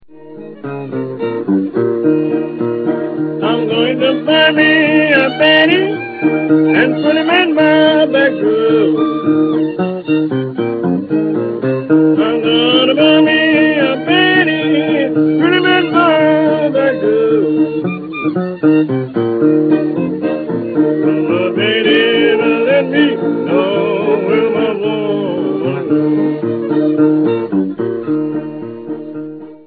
String Bands, Songsters and Hoedowns
Listen to their dazzling breakdown Black Bayou
fiddle
Overall then, a lovely and important collection - fine graphics, remarkably good sound and of course fascinating and invigorating music.